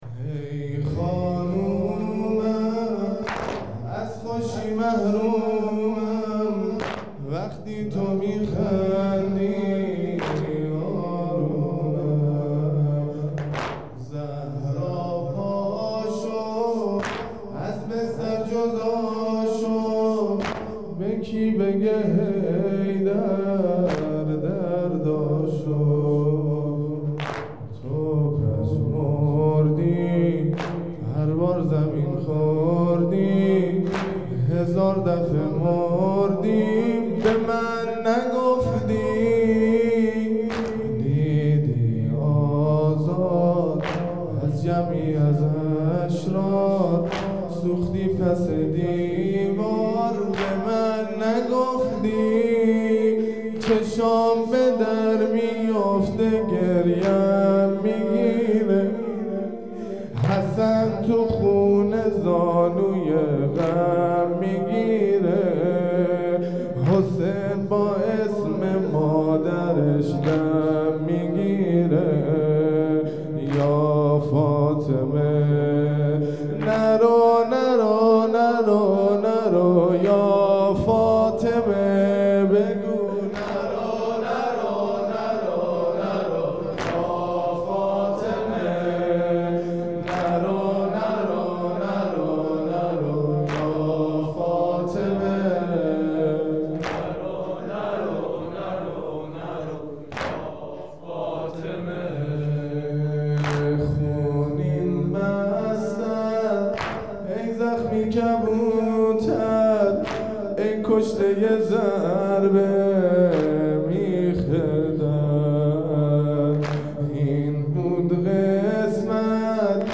مطیع امر گزارش_صوتی فاطمیه 99(روایت75روز) مداحی شهادت حضرت فاطمه زهرا ( س )